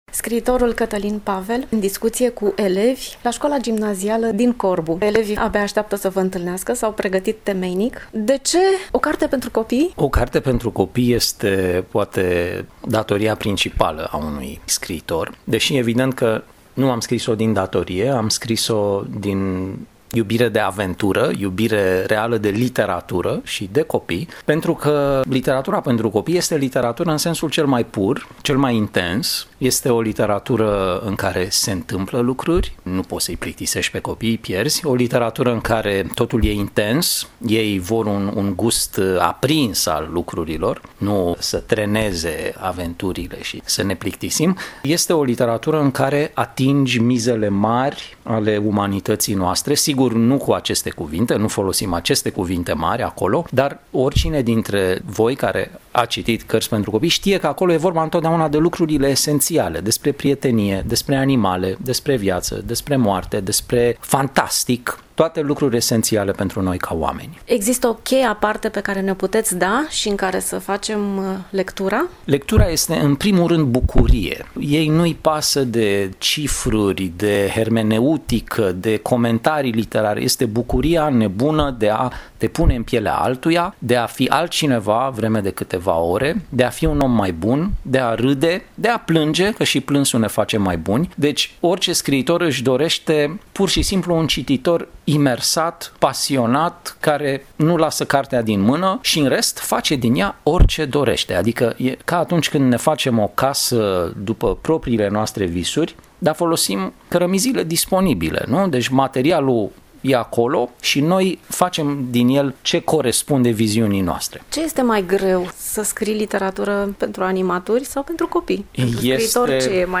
Am întrebat la finalul interviului și despre «Elefantul lui Carol cel Mare. Un anti-manual de istorie», apărut la Editura Art.
SCRIITORUL-CATALIN-PAVEL.mp3